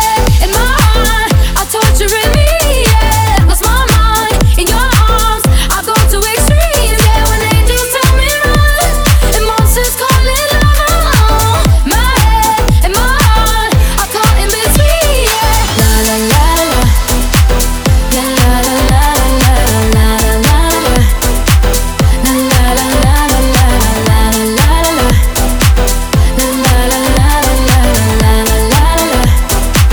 • Pop
pop and dance song